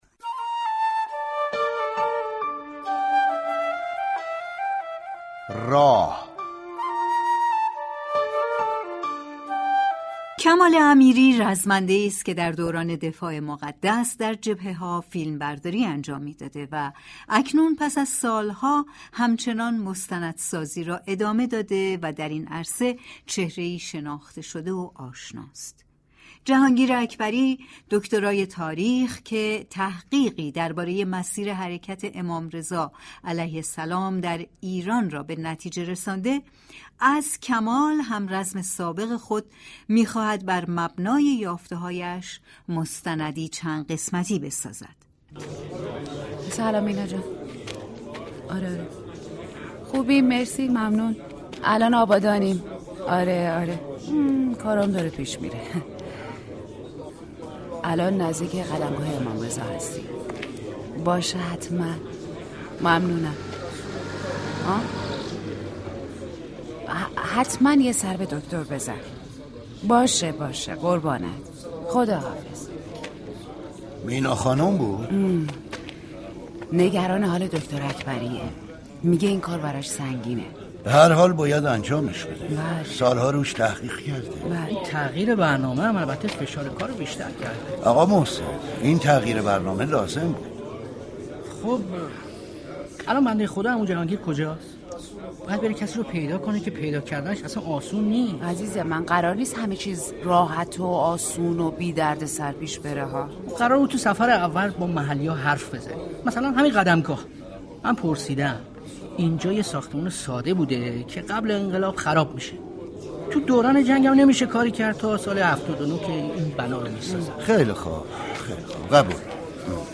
از 28 آبان ماه ، شنونده نمایش رادیویی